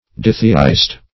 Ditheist \Di"the*ist\, n. One who holds the doctrine of ditheism; a dualist.
ditheist.mp3